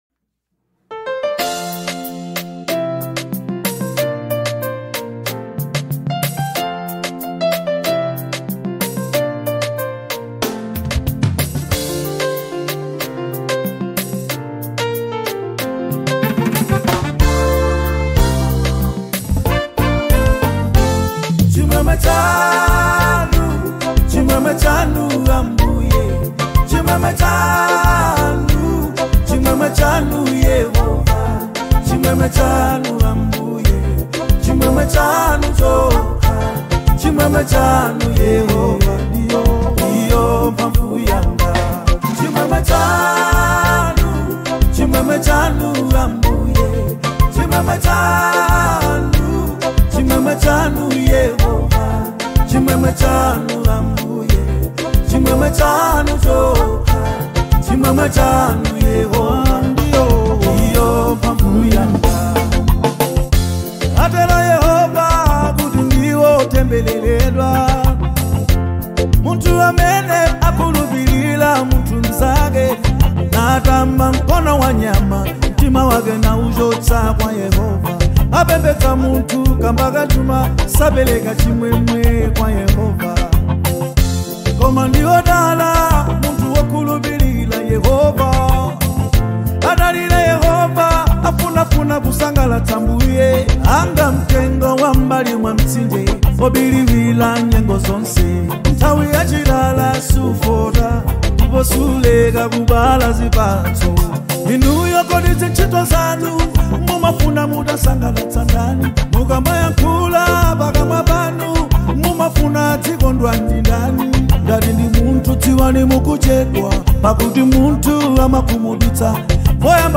Malawian singer-songwriter and Afro-fusion icon